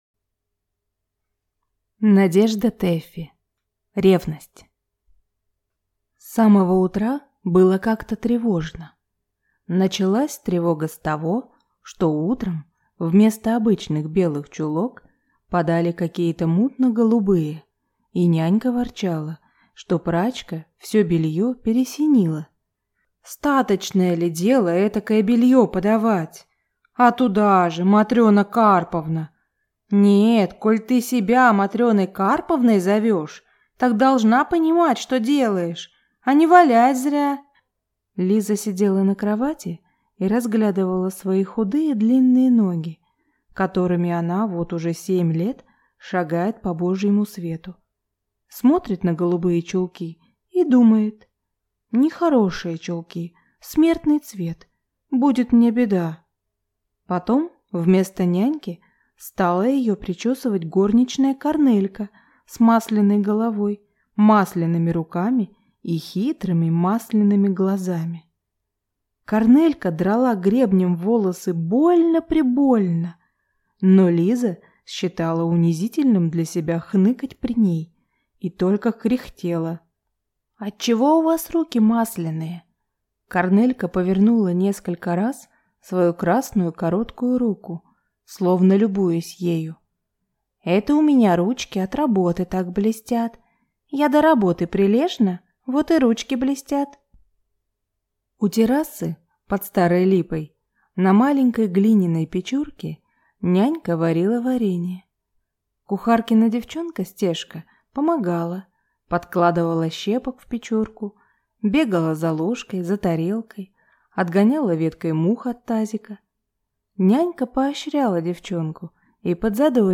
Аудиокнига Ревность | Библиотека аудиокниг